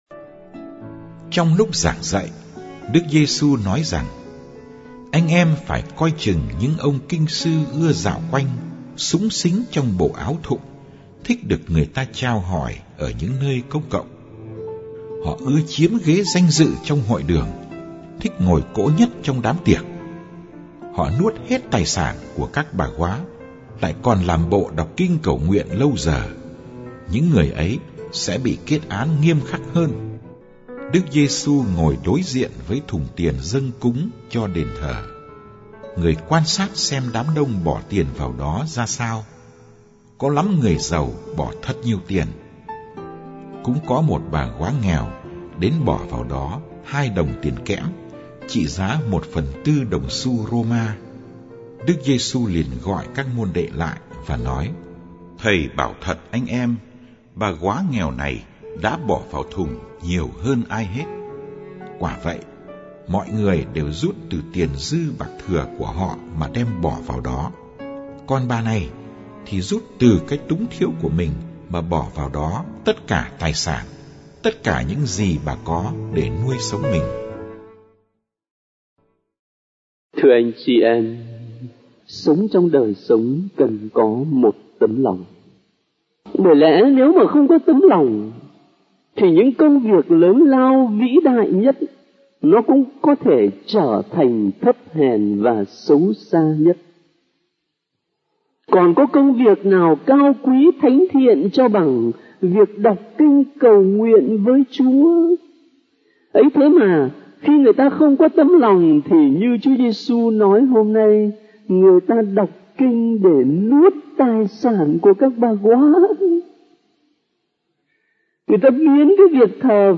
* Ca sĩ: Gm. Phêrô Nguyễn Văn Khảm
* Thể loại: Nghe giảng